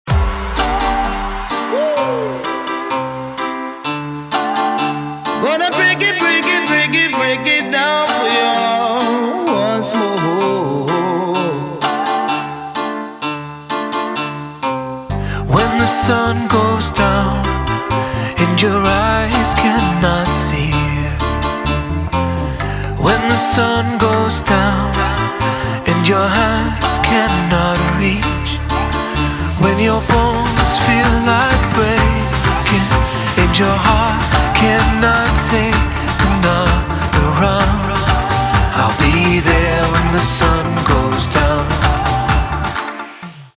• Качество: 320, Stereo
мужской вокал
dance
спокойные